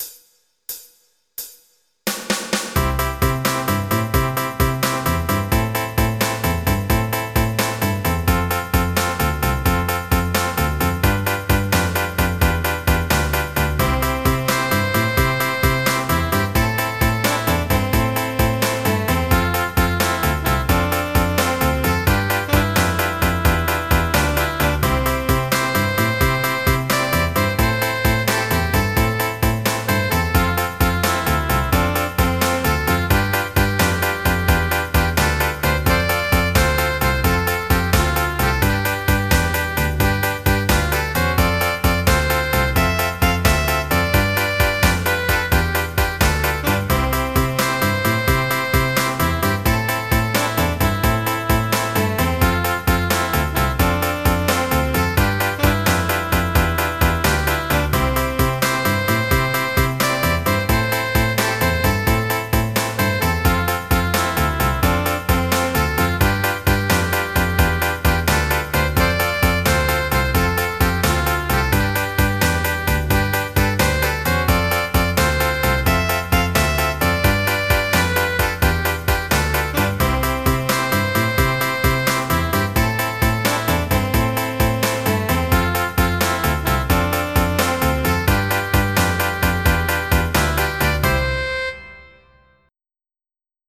MIDI Music File
Type General MIDI
50S_ROCK.mp3